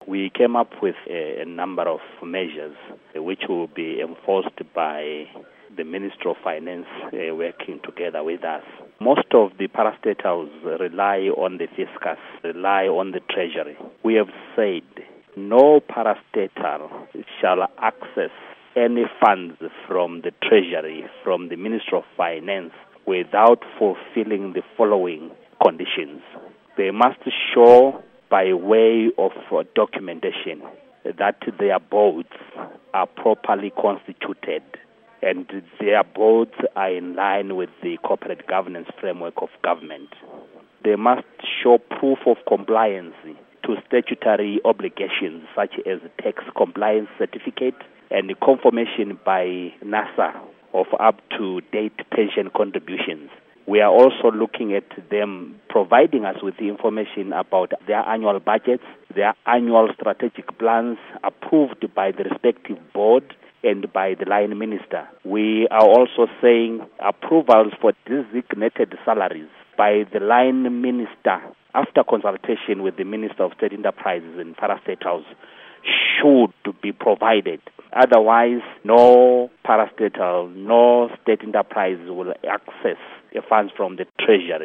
Interview With Gorden Moyo